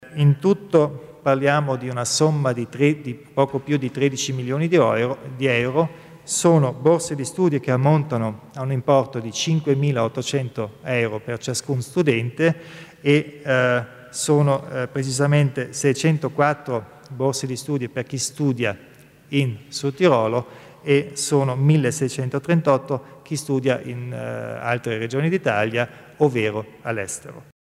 Il Presidente Kompatscher spiega le novità nel settore delle borse di studio